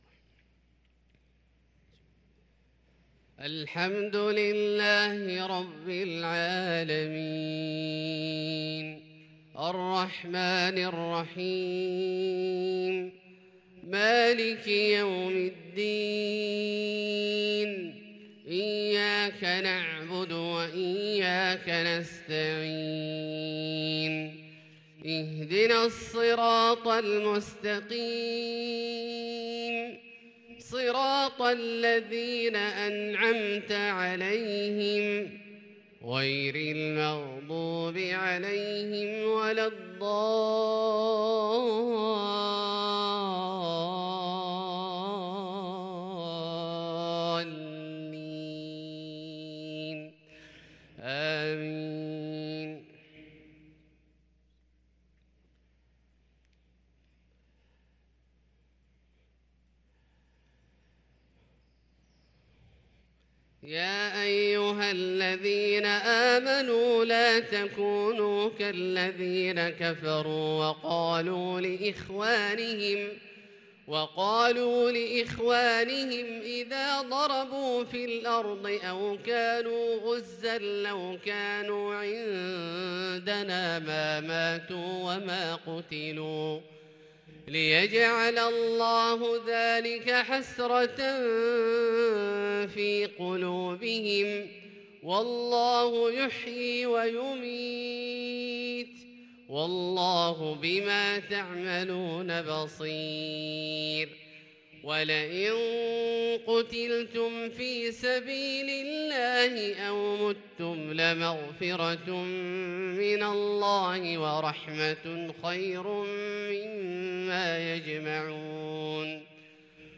صلاة الفجر للقارئ عبدالله الجهني 26 جمادي الأول 1444 هـ
تِلَاوَات الْحَرَمَيْن .